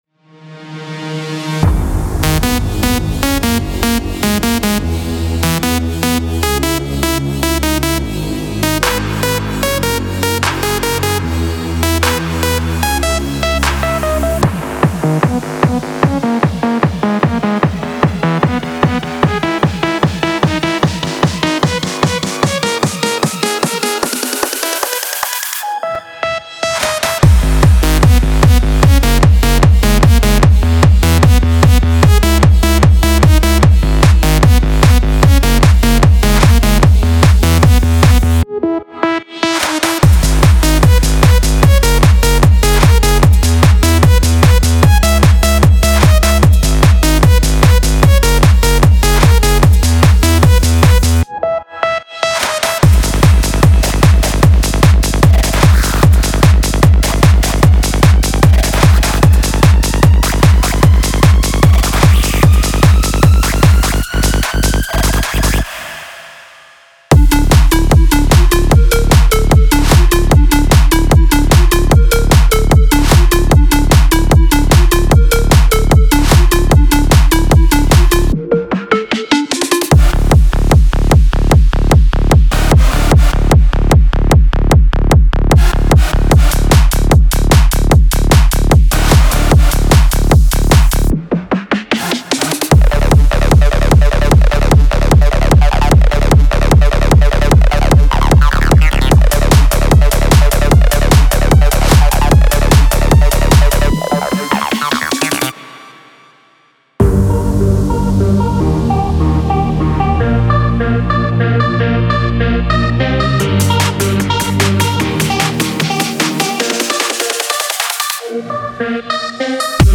Genre:Hard Dance
パンチのあるドラム、力強いベースライン、そして容赦ないエネルギーに満ちた真の際立つコレクションです。
リズムレイヤーを豊かにするために、深みとドライブ感を加える多様なパーカッションも用意されています。
デモサウンドはコチラ↓